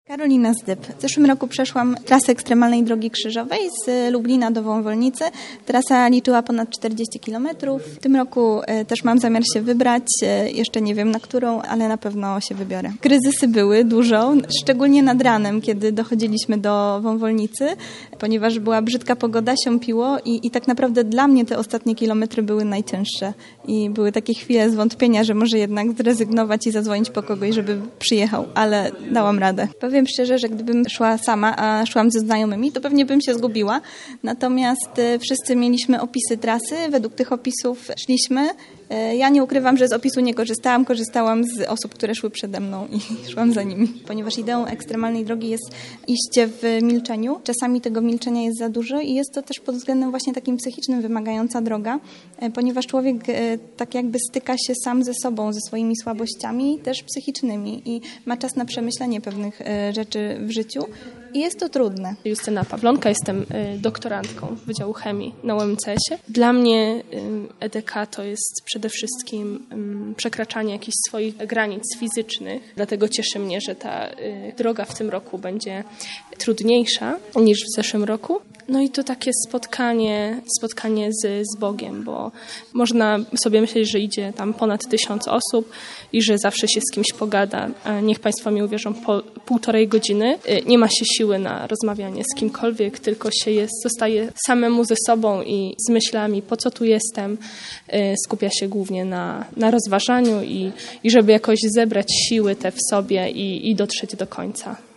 EDK uczestniczka